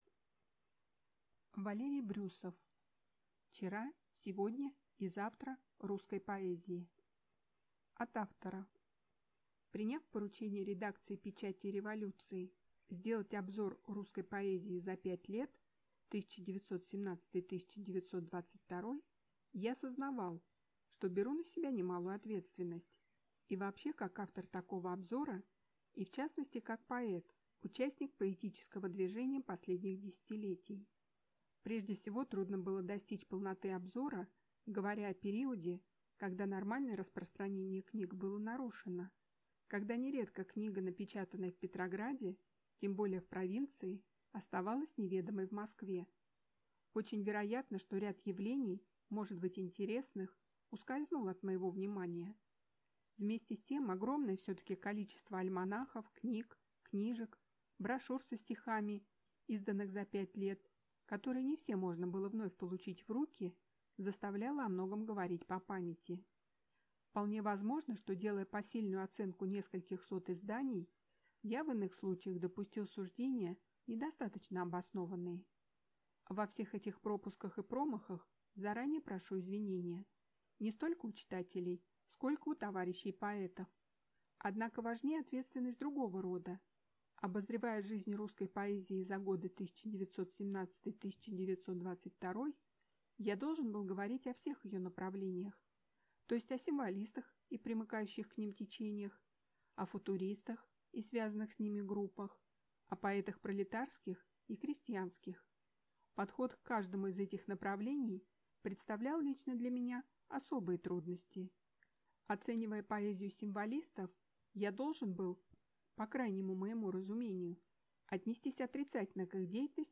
Аудиокнига Вчера, сегодня и завтра русской поэзии | Библиотека аудиокниг